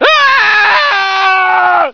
scream20.ogg